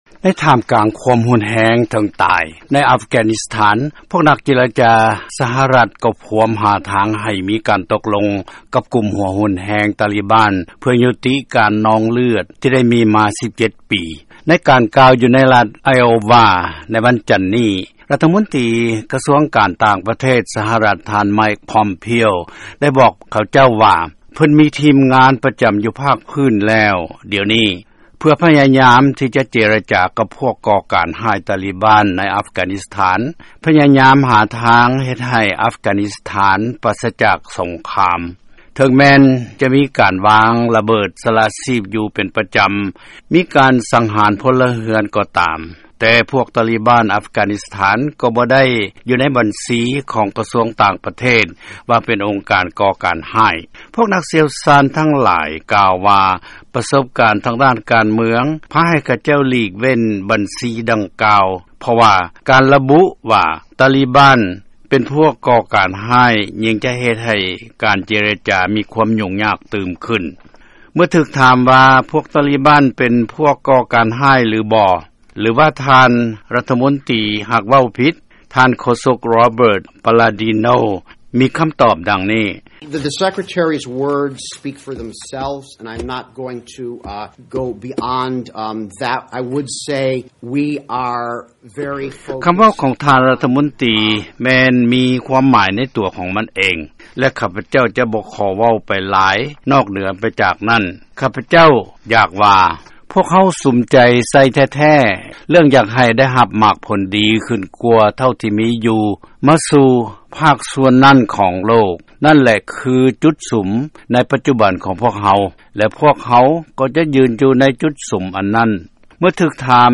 ເຊີນຟັງລາຍງານກ່ຽວກັບກອງປະຊຸມສັນຕິພາບໃນອັຟການິສຖານ